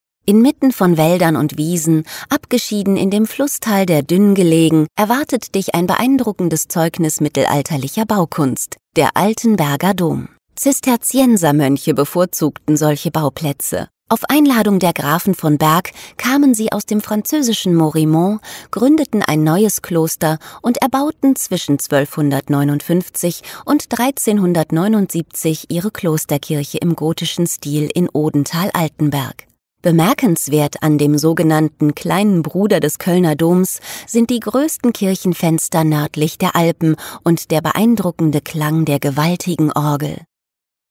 audioguide-altenberger-dom.mp3